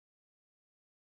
stille1000ms.mp3